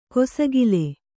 ♪ kosagile